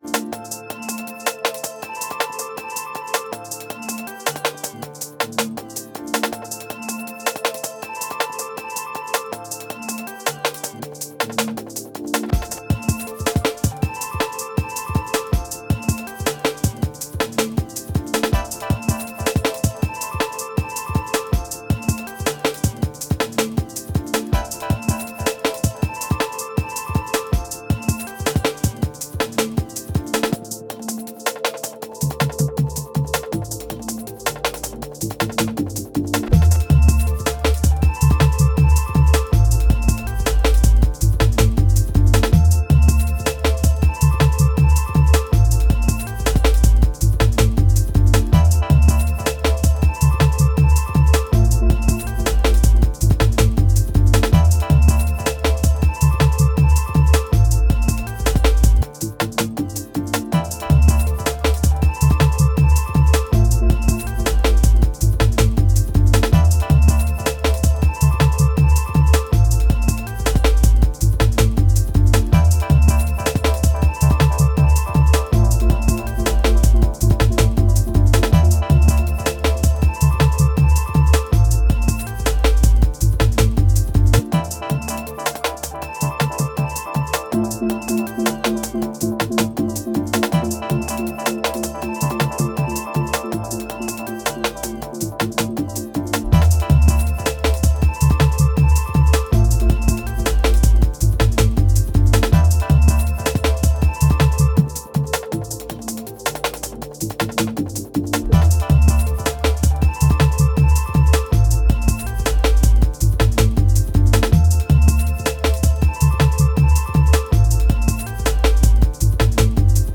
Drum n' Bass Battle #2 (Arcade Edition) [Playlist is up!
Sampled the goat Metroid Prime for some ambient vibes as a base.